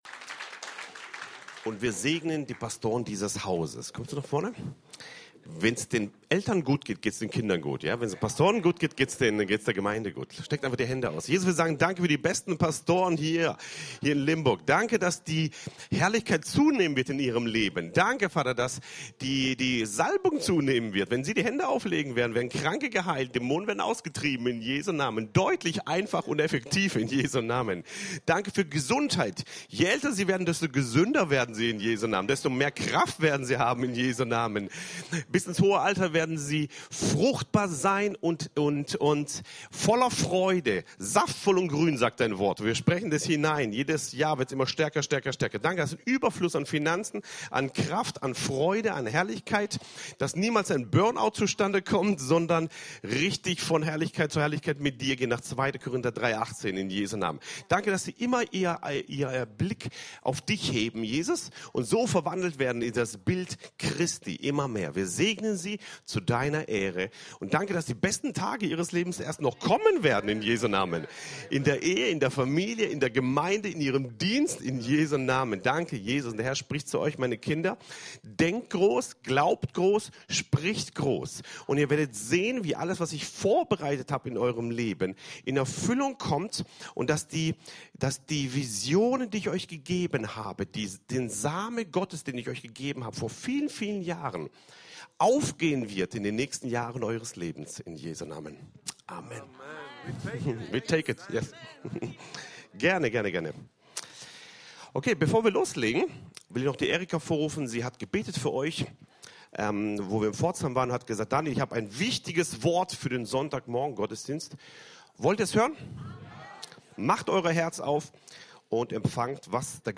CCLM Predigten